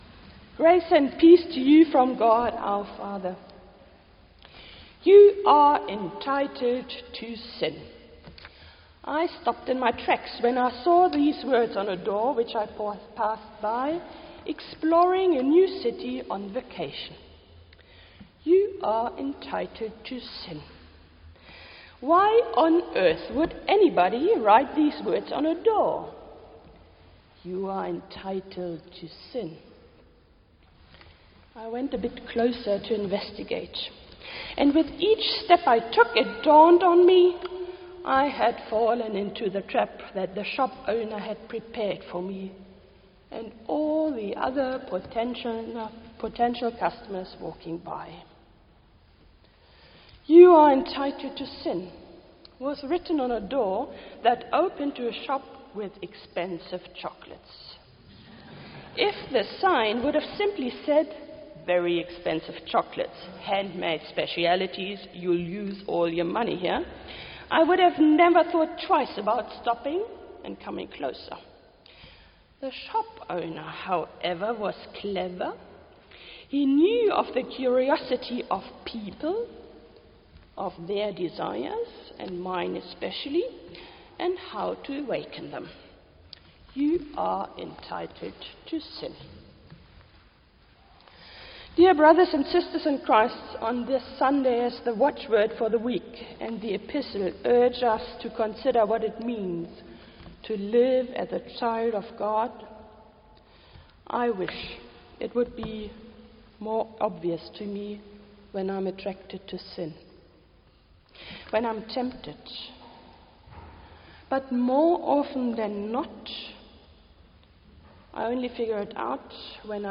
2nd Sunday after Easter